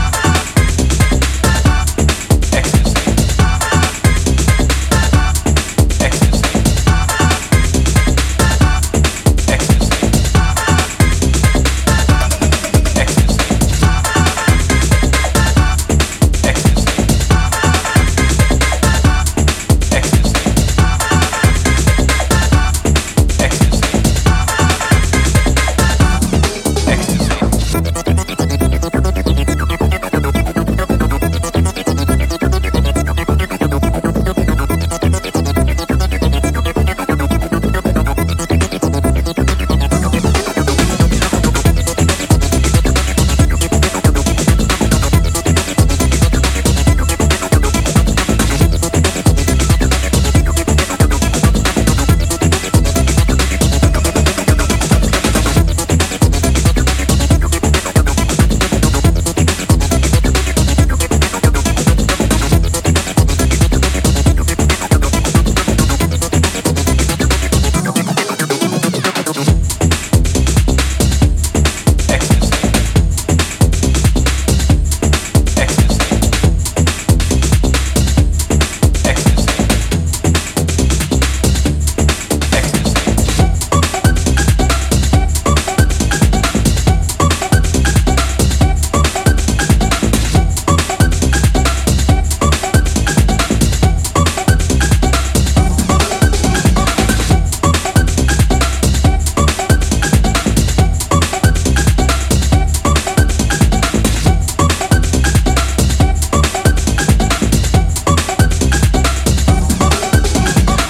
a composition that showcases true rave textures.